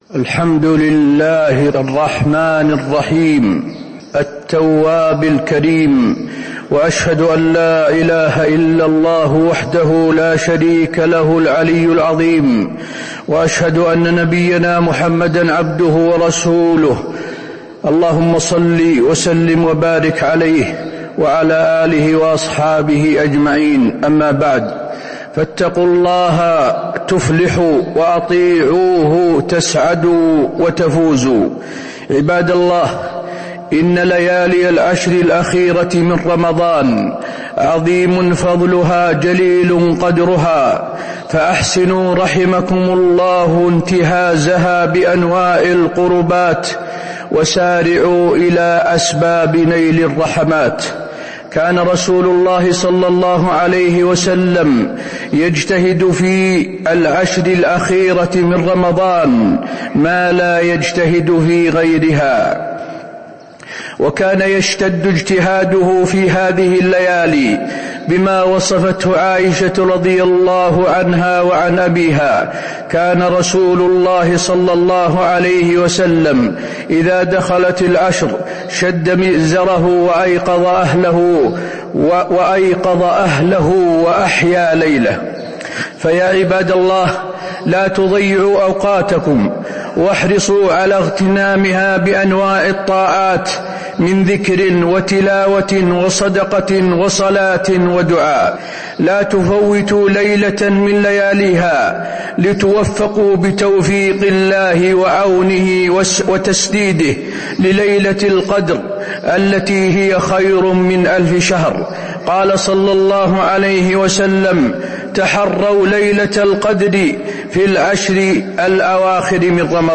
تاريخ النشر ٢٣ رمضان ١٤٤٤ هـ المكان: المسجد النبوي الشيخ: فضيلة الشيخ د. حسين بن عبدالعزيز آل الشيخ فضيلة الشيخ د. حسين بن عبدالعزيز آل الشيخ العشر الأواخر فضائل وأحكام